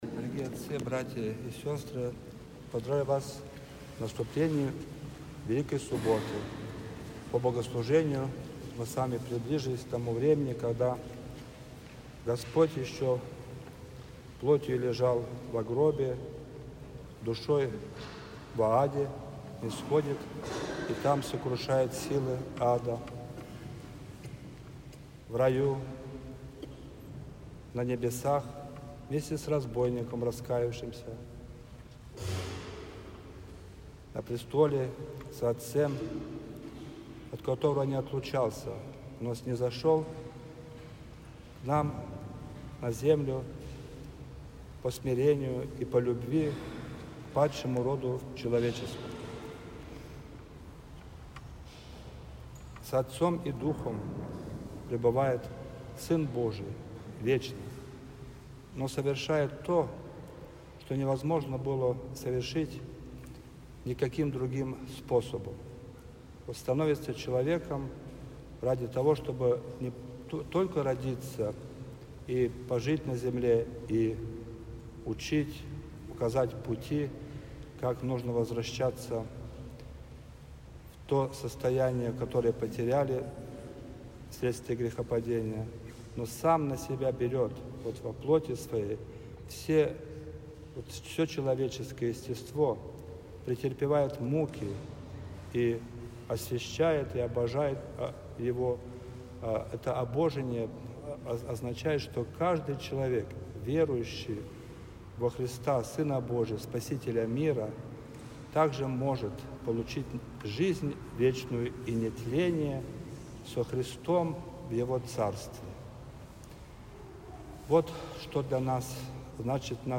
Вечером была совершена утреня Великой Субботы.
Утреня-Великой-Субботы.mp3